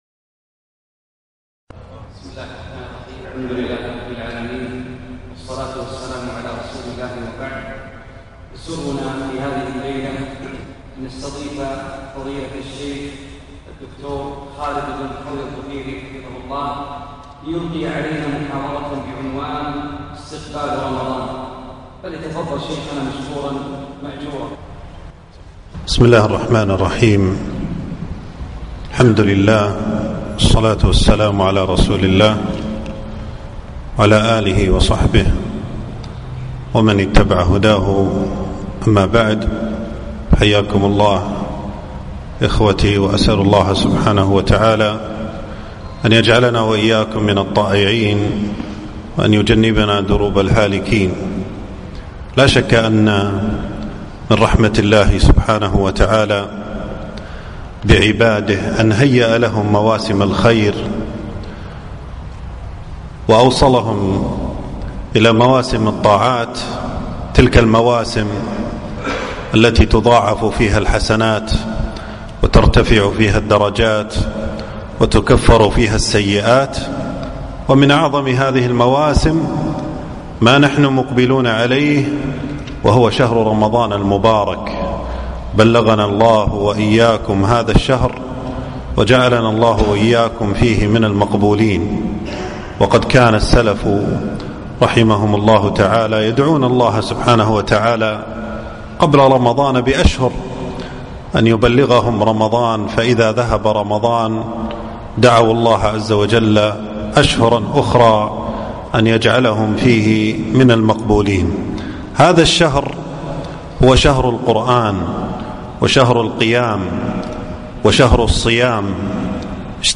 محاضرة - استقبال رمضان